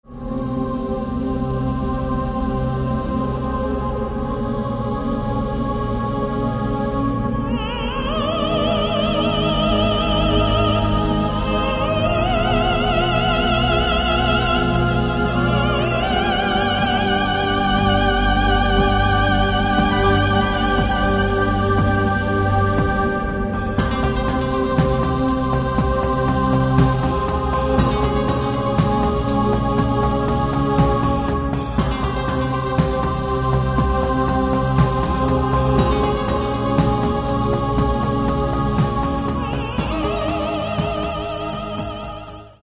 Каталог -> Классическая -> Нео, модерн, авангард
Внутри нее – просторно и легко.